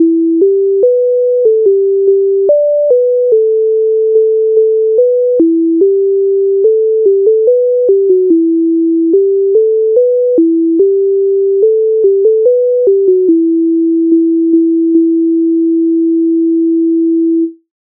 MIDI файл завантажено в тональності e-moll
Вишні-черешні розвиваються Українська народна пісня з обробок Леонтовича с. 157